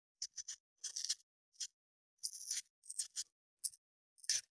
496桂むき,大根の桂むきの音切る,包丁,厨房,台所,野菜切る,咀嚼音,ナイフ,調理音,
効果音厨房/台所/レストラン/kitchen食材